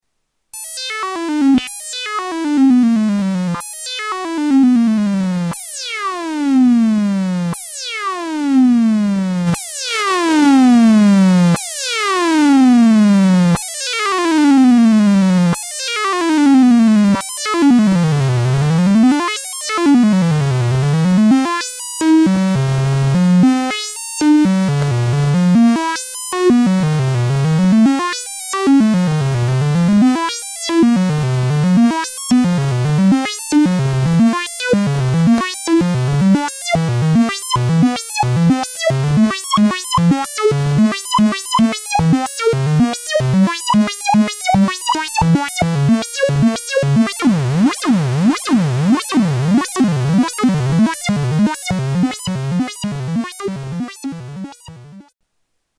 Tags: Sound Effects EML ElectroComp 101 EML101 ElectroComp 101 Synth Sounds